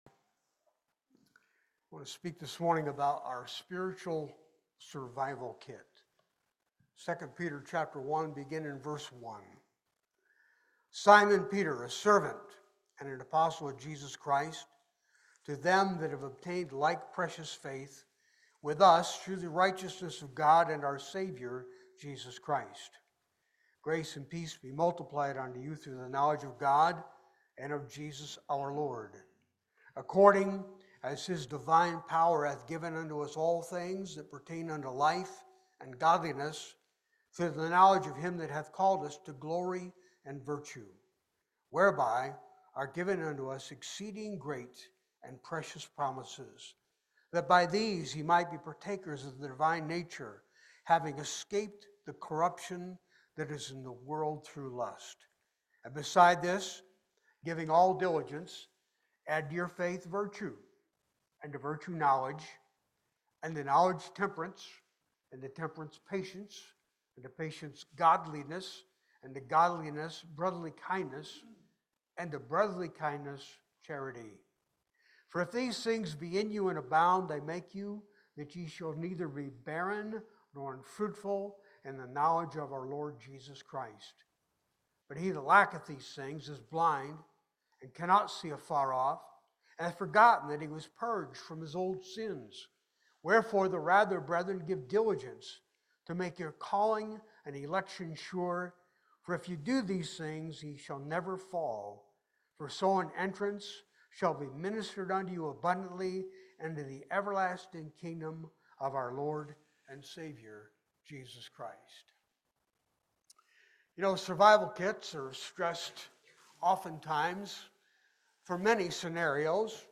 Passage: II Peter 1:1 Service Type: Sunday Worship